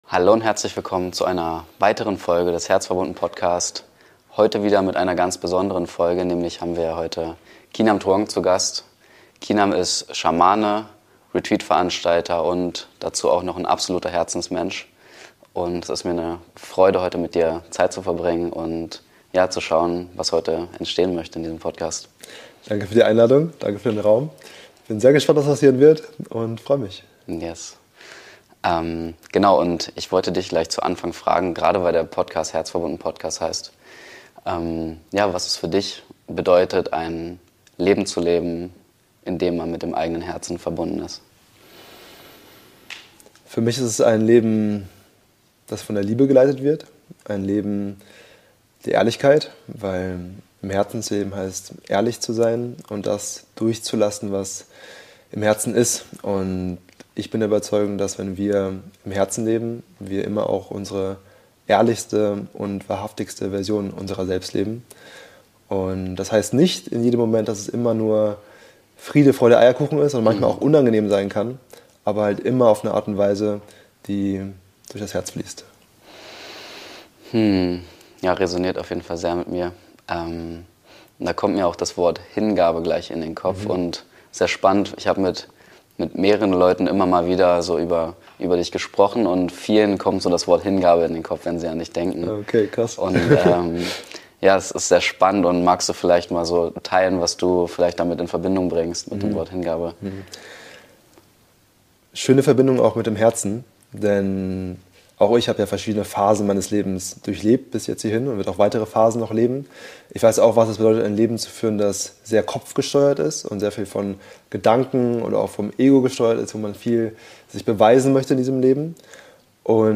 Im Einklang mit deiner Wahrheit leben - ein Interview